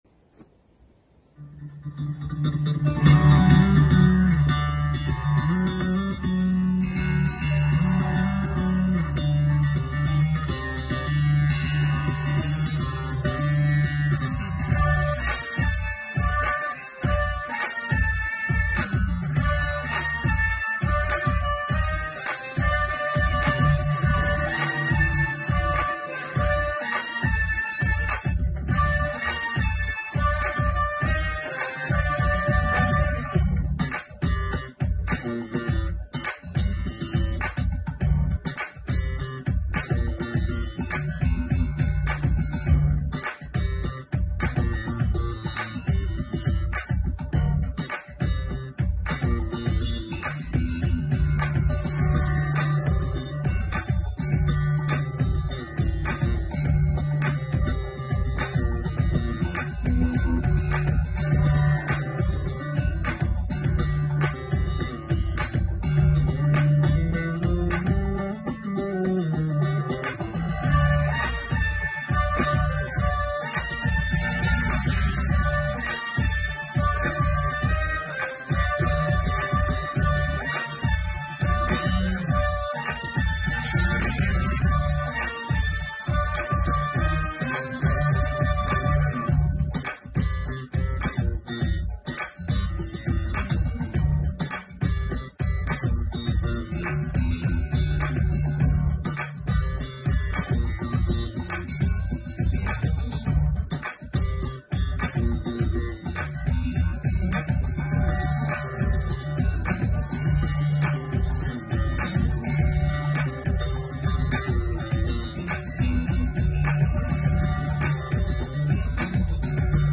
inv300hz.mp3